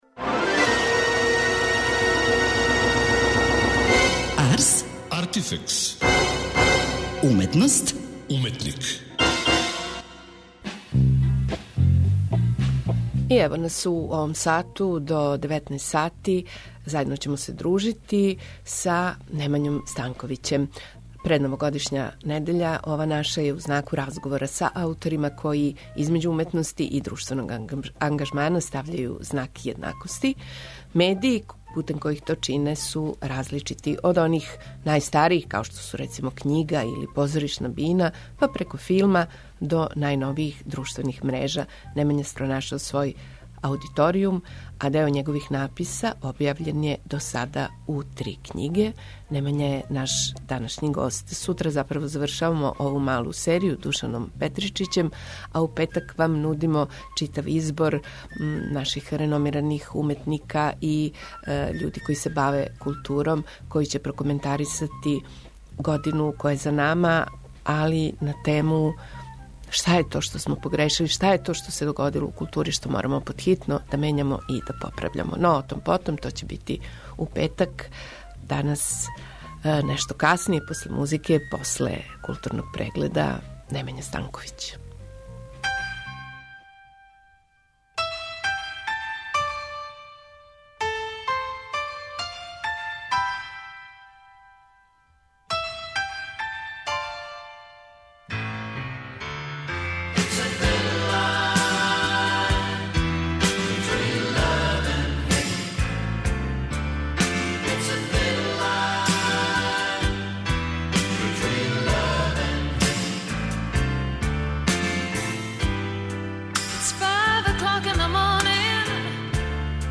Предновогодишња недеља биће у знаку разговора са ауторима који између уметности и друштвеног ангажмана стављају знак једнакости.